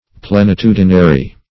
Search Result for " plenitudinary" : The Collaborative International Dictionary of English v.0.48: Plenitudinary \Plen`i*tu"di*na*ry\, a. Having plenitude; full; complete; thorough.